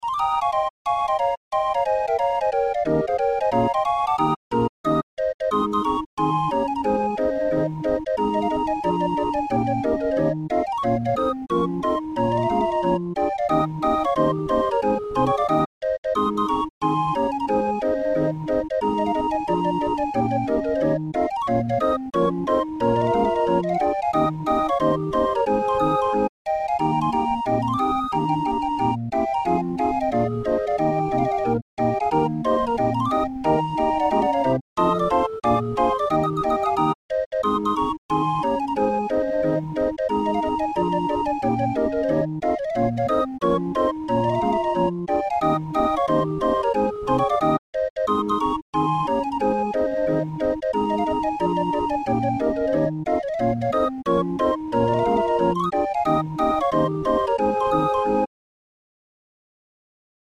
Musikrolle 31-er Raffin